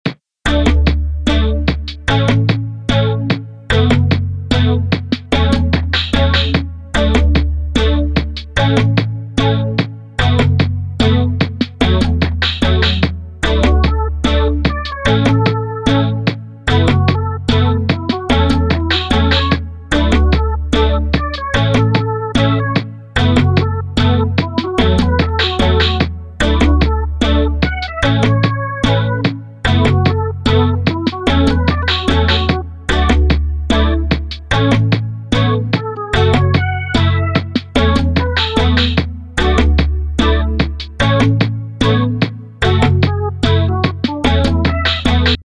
Cute, lightheareted little loopable background tune made for a puzzle game jam. I think it has a kind of island vibe.